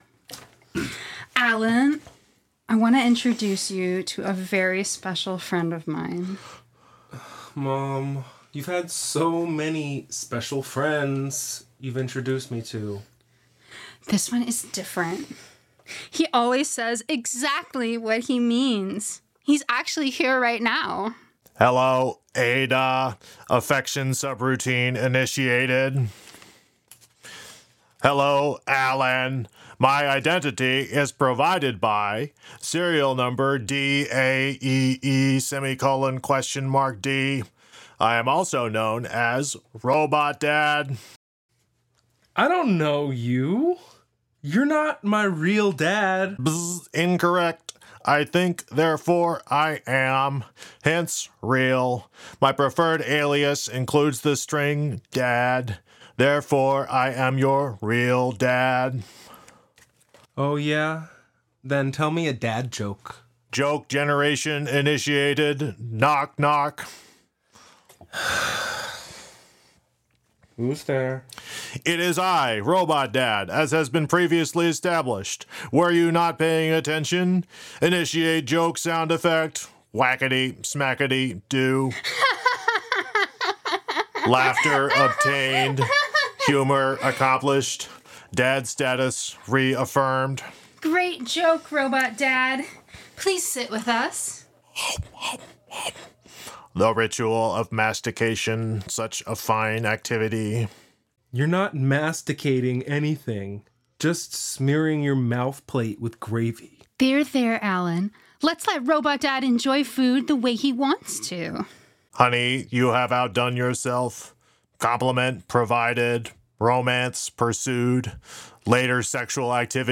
Format: Audio Drama
Voices: Solo
Genres: Comedy